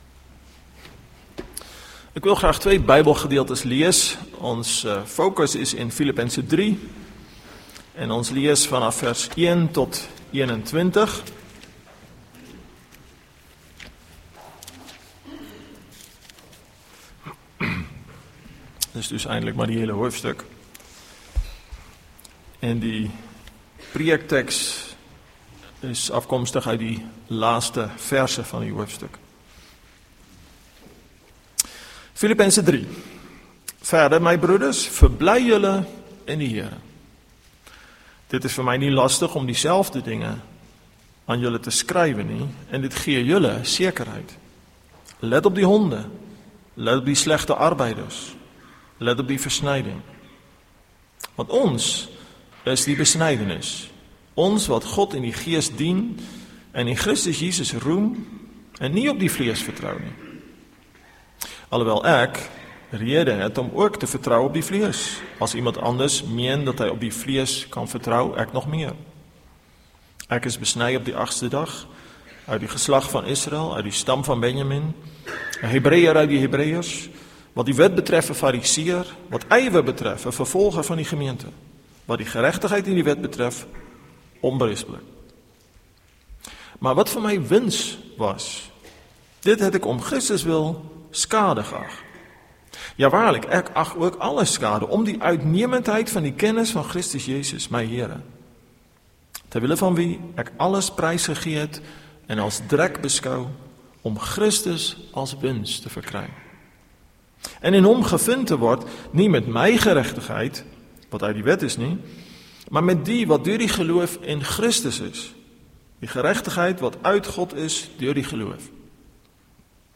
Link Preek Inhoud